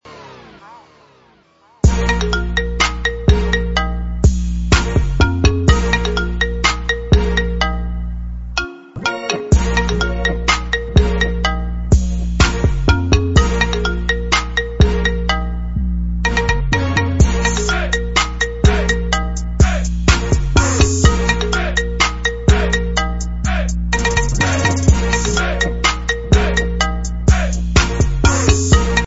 دانلود آهنگ زنگ موبایل ساده و شیک, ملایم و خاص